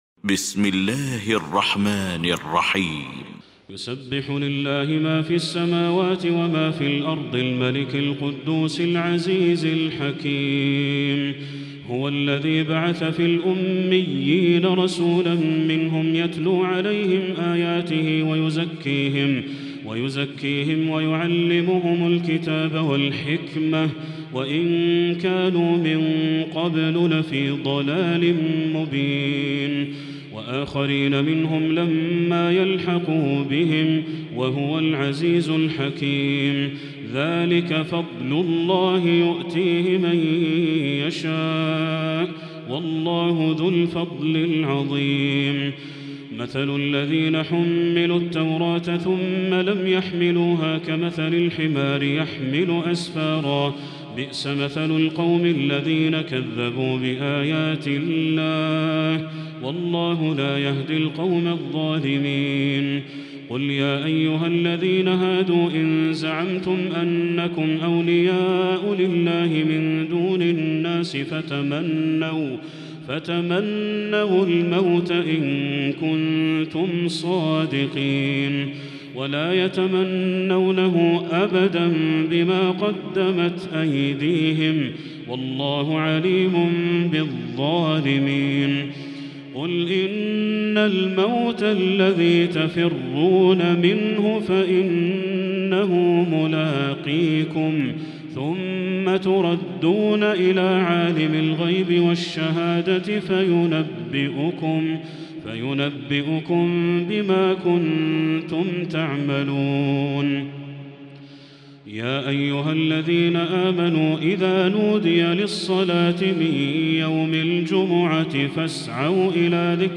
المكان: المسجد الحرام الشيخ: بدر التركي بدر التركي الجمعة